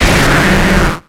Cri de Volcaropod dans Pokémon X et Y.